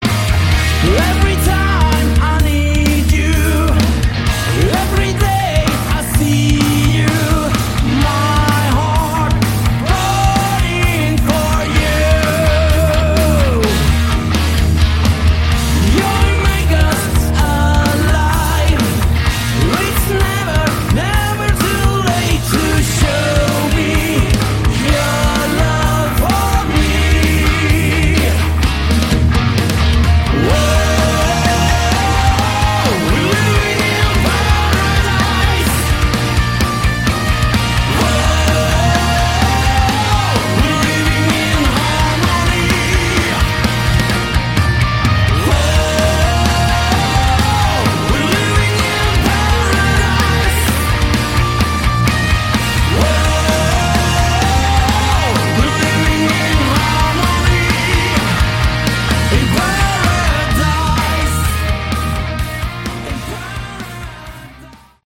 Category: Hard Rock
lead vocals
lead guitar
bass
drums